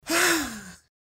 Звуки подарка
На этой странице собраны звуки подарков: от шелеста оберточной бумаги до радостных возгласов при вскрытии сюрприза.